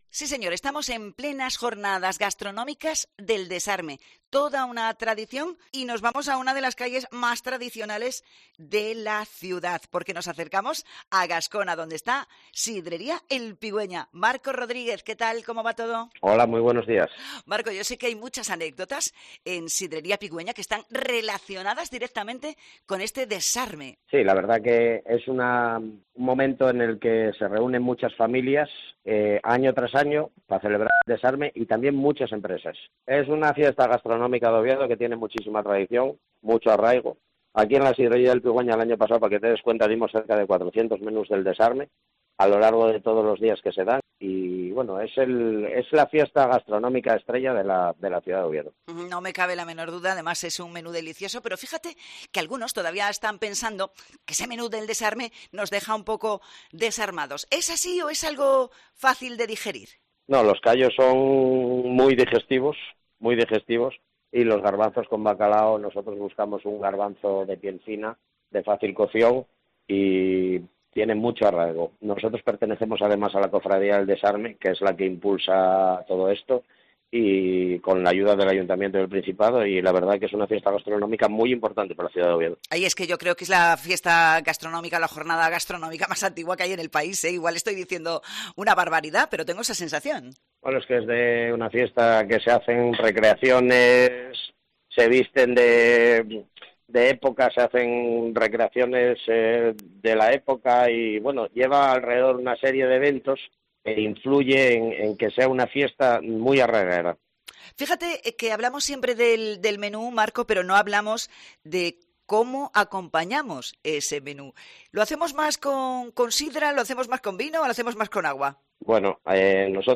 Jornadas del Desarme: entrevista